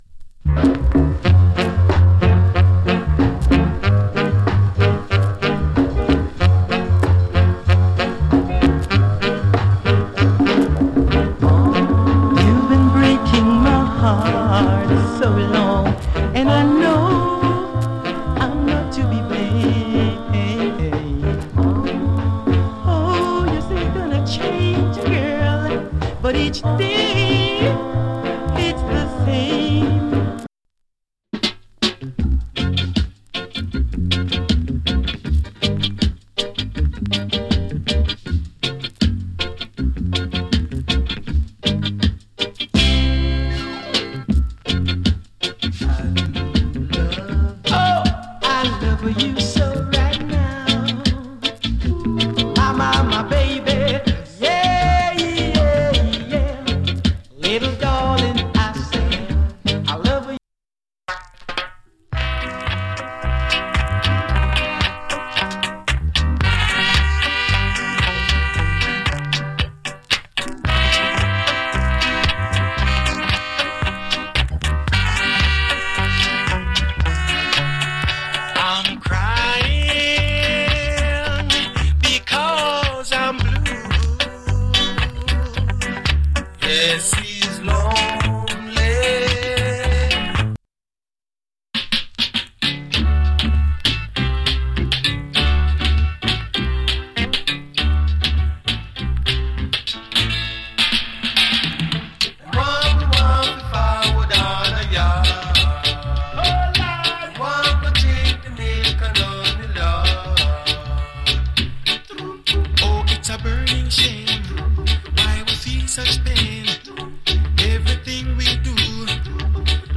プレスによるノイズが入る部分有り。